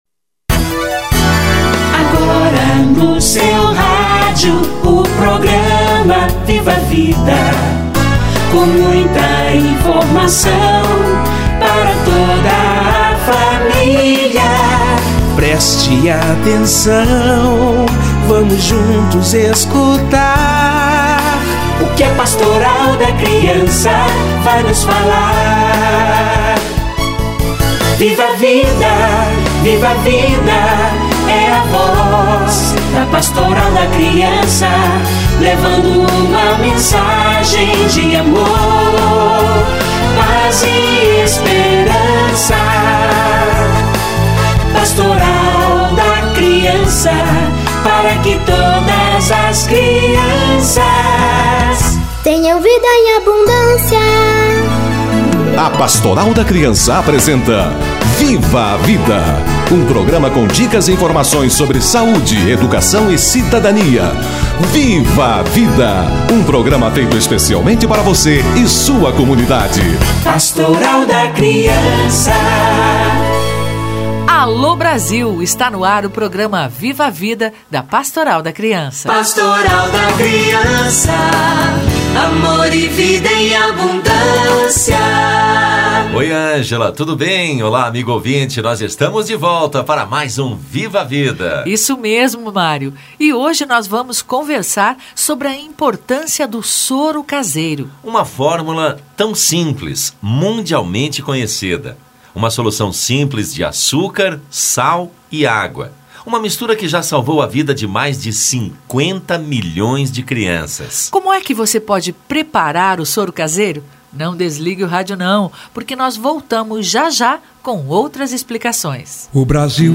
Campanha do Soro Caseiro - Entrevista